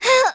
Artemia-Vox_Damage_01.wav